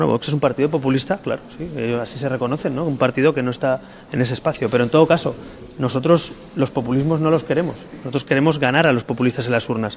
Una de les proves són aquests dos petits talls de l’hemeroteca.